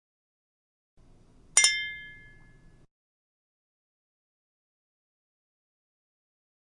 描述：通过将叉子和勺子叮当作响来创造这种声音，以模拟悬挂或移动的手铐。用Tascam DR05录制
Tag: 碎屑 叮当 钢铁 手铐 勺子 翻箱倒柜 切开刀 餐具 金属 命中 抽屉 沙沙声 警察 警察